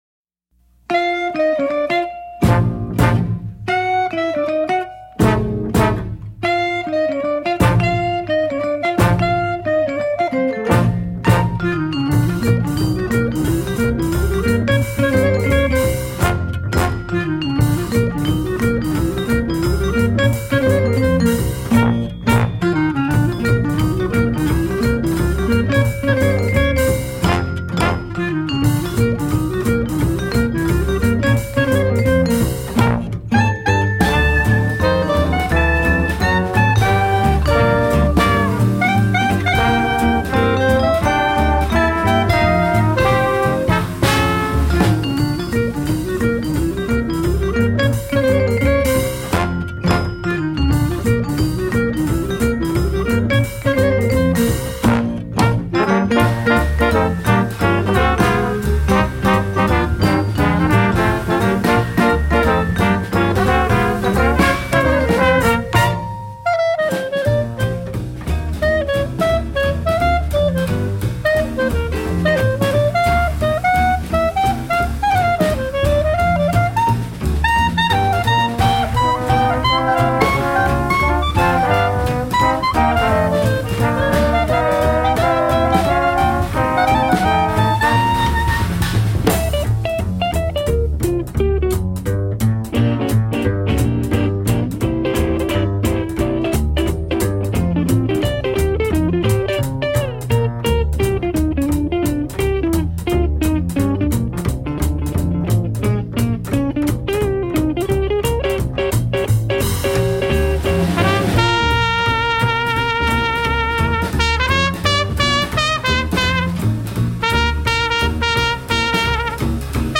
Vintage swing in the 21st century.